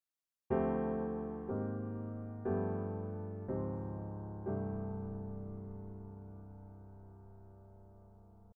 As you can hear, these major 7th chords sound surprisingly warm and (dare I say) smooth.
chromatic-major-chords-ii-V-I-in-c-major.mp3